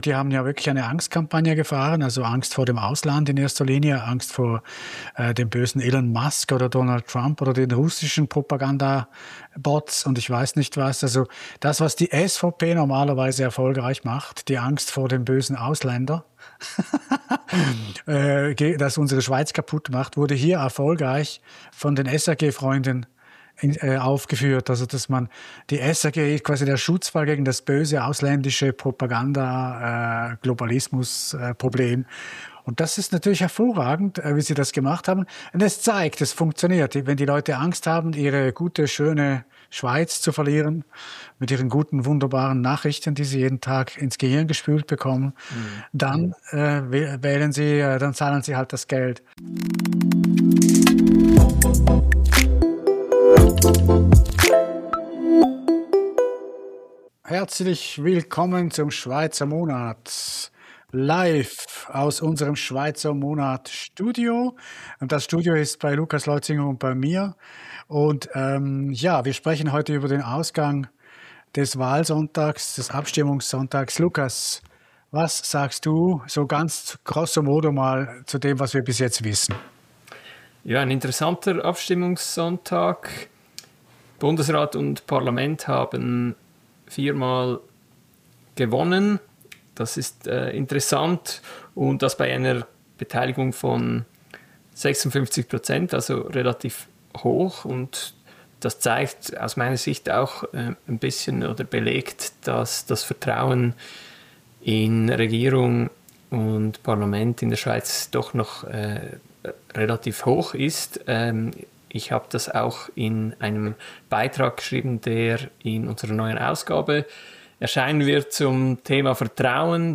Ein Gespräch über direkte Demokratie, Medienvertrauen, Klimapolitik und die politische Kultur der Schweiz.